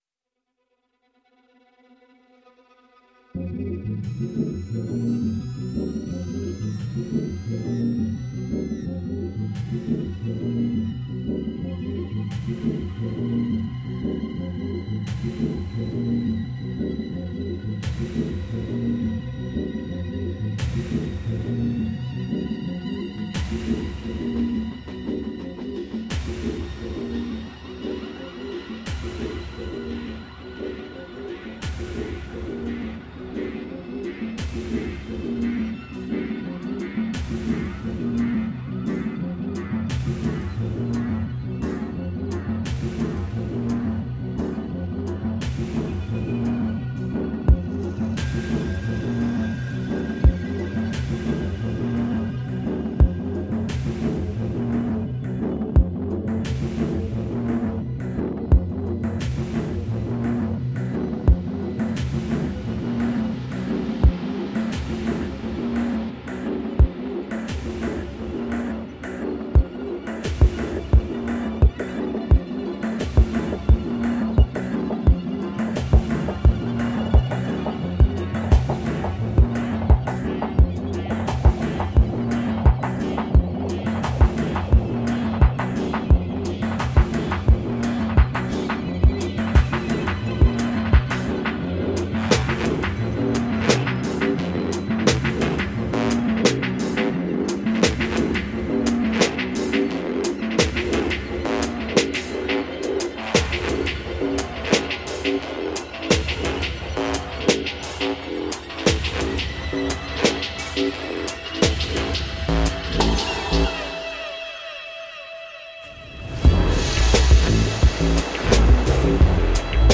Psyche-dub electrical pirate wobble.
Tagged as: Electronica, Ambient, Darkwave